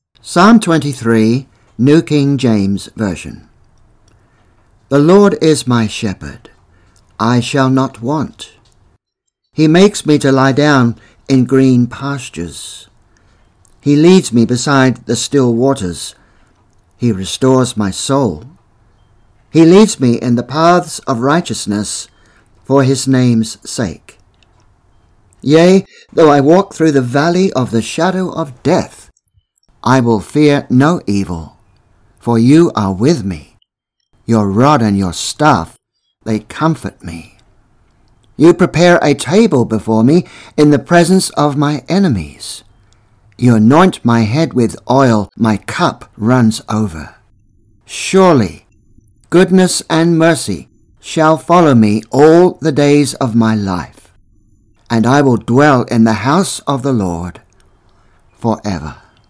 Psalm 23 - A Reading